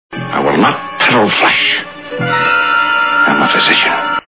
Star Trek TV Show Sound Bites